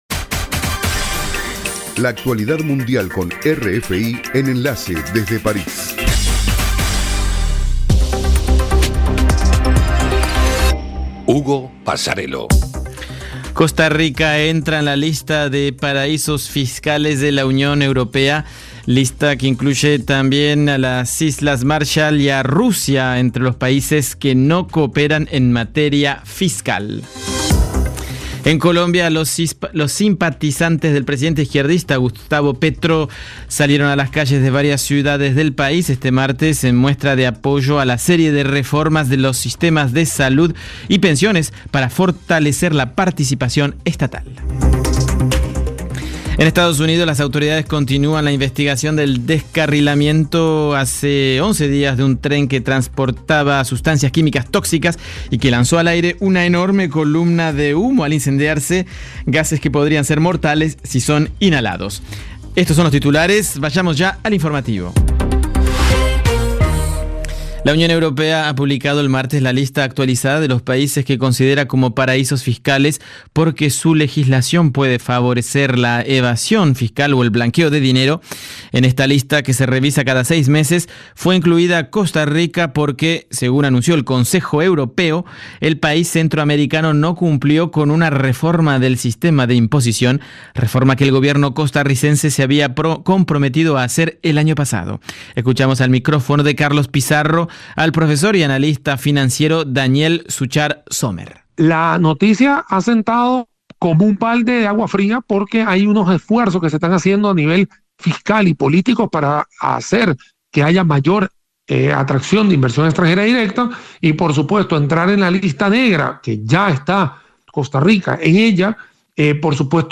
Programa: RFI - Noticiero de las 20:00 Hs.